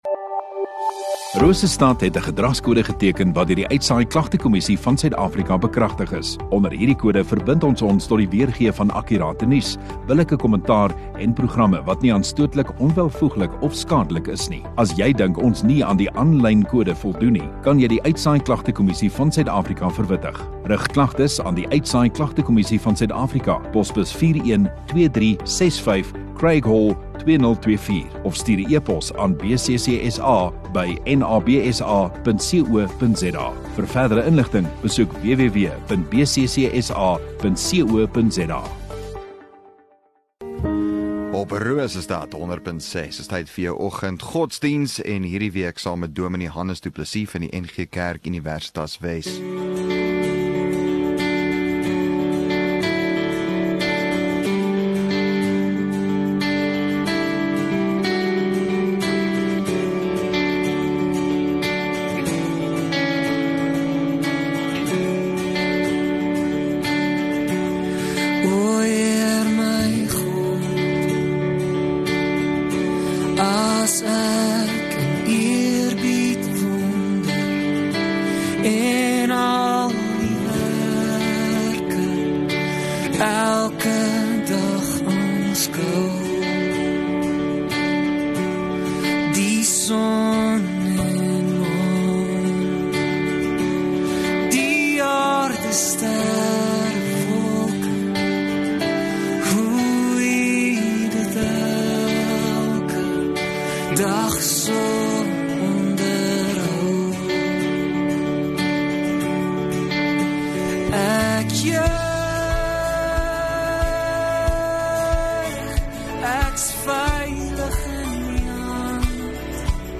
23 May Vrydag Oggenddiens